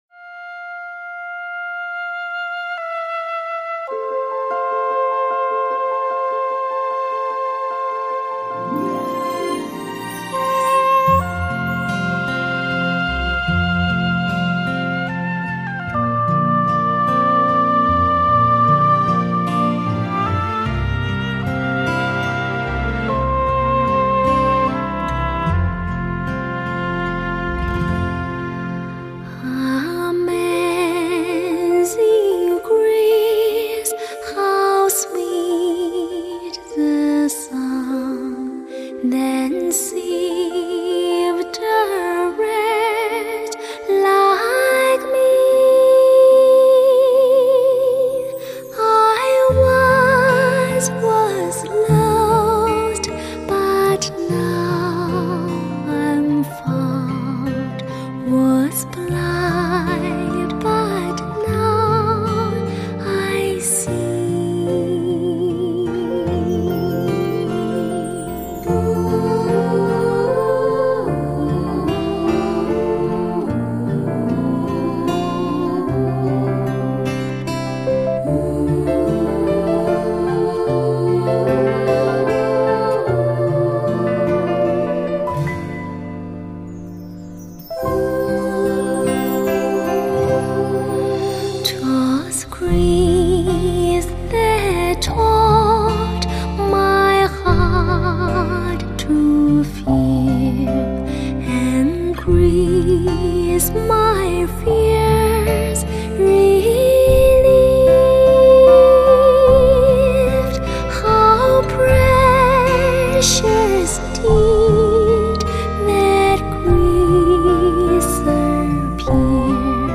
音乐类型:HI-FI 天籁人声
音质是如此美妙，清透甜美悠扬婉转，不沾凡尘烟火，恰似仙界妙音。
輕柔柔的歌聲 把奇異的恩典送給大家 謝謝即往